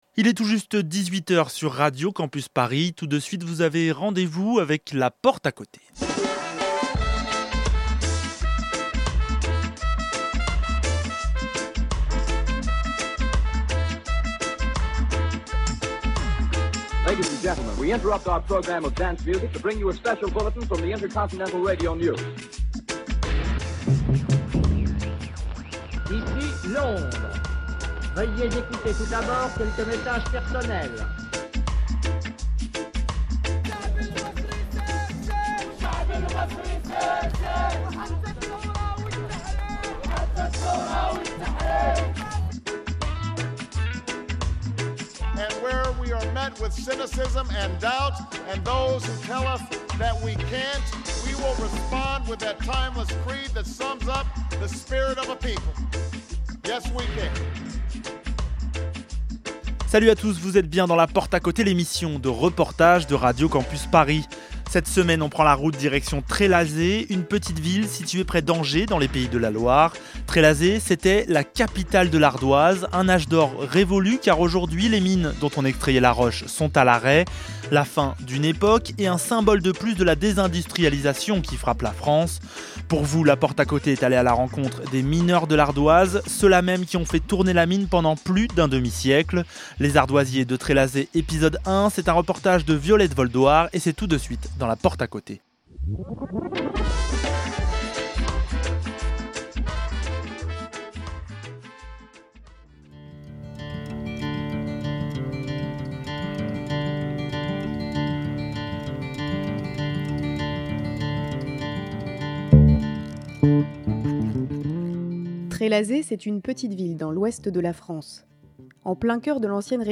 Pour vous La Porte à Côté a rencontré ces mineurs de l’ardoise. Dans ce premier épisode de ce reportage en deux parties. Ils racontent leur histoire, leur lieu de travail et leur combat.